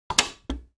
Tags: theater